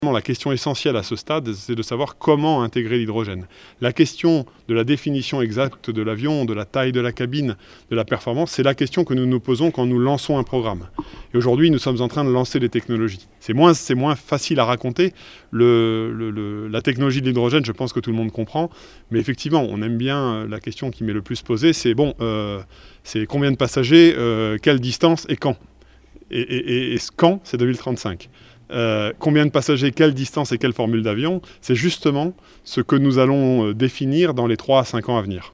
Airbus mise sur l'hydrogène - Reportage Sud Radio